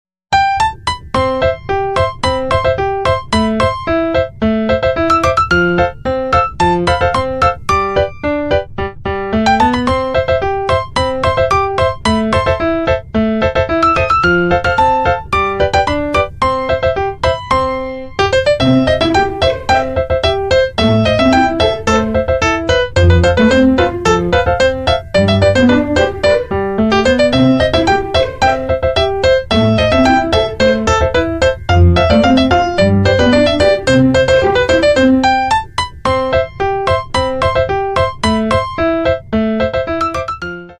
Single Shaft Shredder for cables sound effects free download
Cable wire recycling machine - Copper wire shredder Raw material: PVC cables